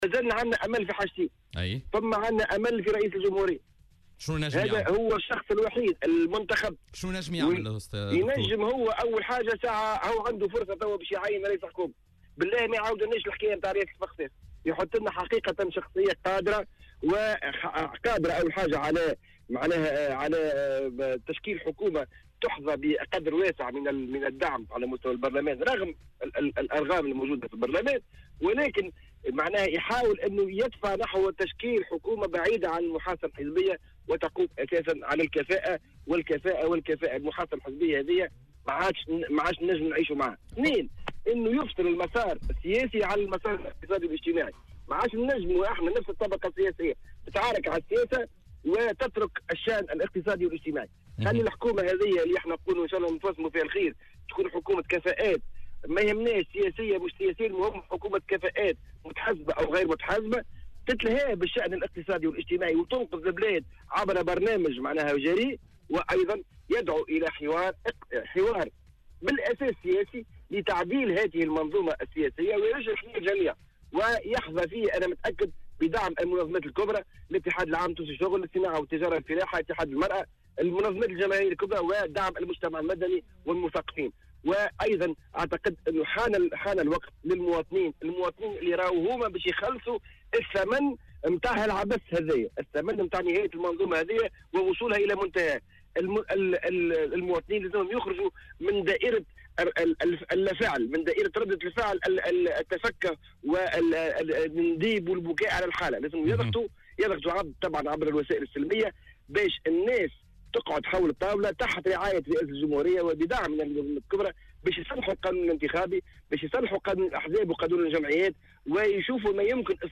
وأضاف في مداخلة له اليوم في برنامج "بوليتيكا" أنه على رئيس الدولة تعيين شخصية قادرة على تشكيل حكومة تحظى بقدر واسع من الدعم في البرلمان وتقوم على الكفاءات وليس المحاصصة الحزبية.